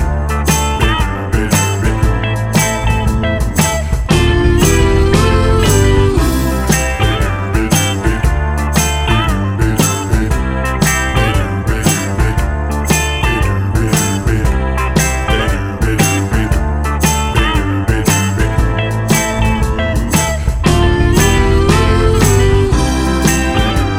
Two Semitones Down Soul / Motown 3:15 Buy £1.50